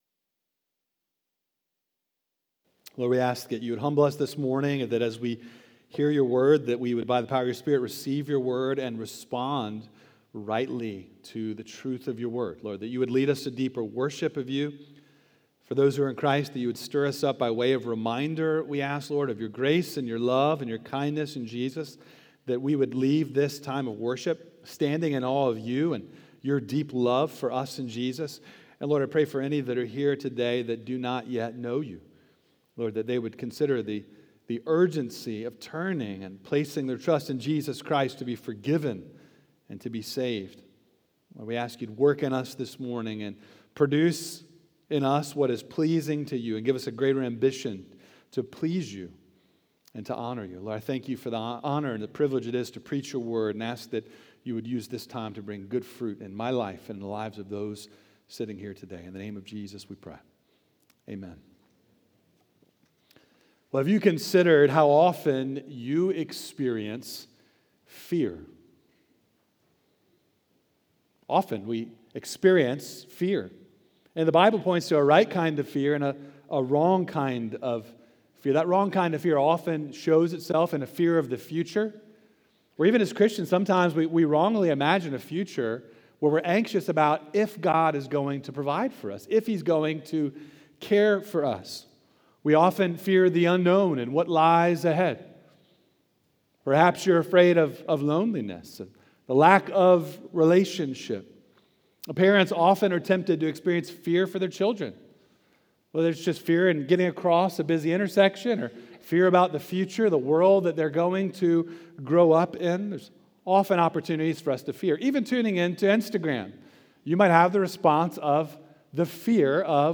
Oakhurst Baptist Church Sermons